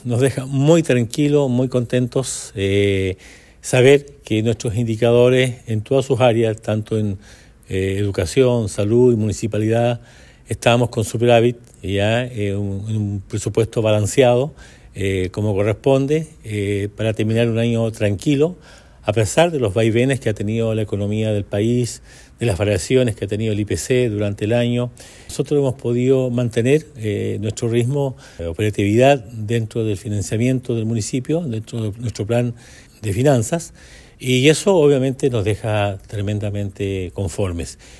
El alcalde Emeterio Carrillo aprovechó la ocasión para resaltar que la presentación de este informe busca entregar tranquilidad a los habitantes de Osorno. El superávit en los sectores de Salud y Educación es especialmente relevante, ya que son áreas clave en el bienestar de la comunidad, lo que demuestra que la gestión municipal ha logrado mantener un equilibrio adecuado entre el gasto y los ingresos.